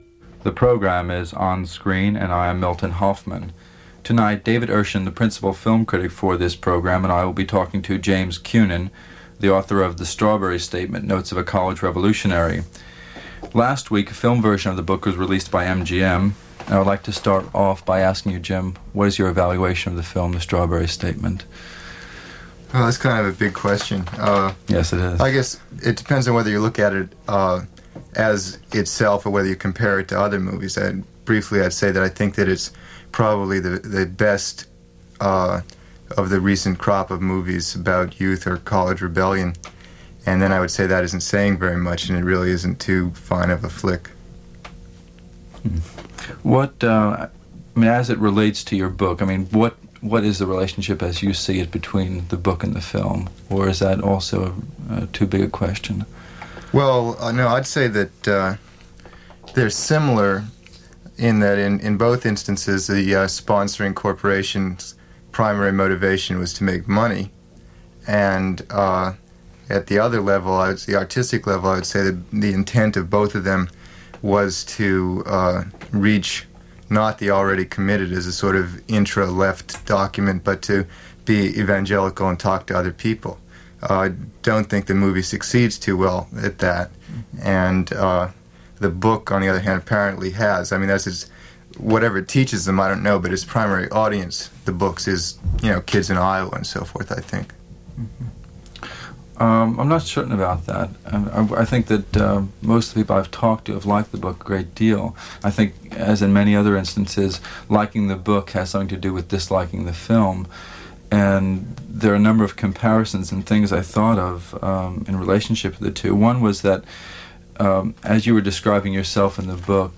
James Simon Kunen Talks About The Strawberry Statement for the program On Screen - broadcast June 26, 1970 - Past Daily Evening Gallimaufry.
Interview from On Screen